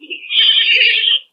パンダ（リーリー）
panda.mp3